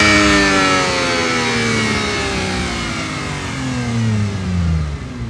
f1_02_decel.wav